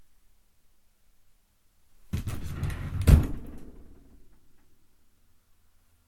Fast/Close Drawer 2
Duration - 6 s Environment - Bedroom, absorption of curtains, carpet and bed. Description - Close, pulled fast, grabs, slams, wooden drawer, bangs close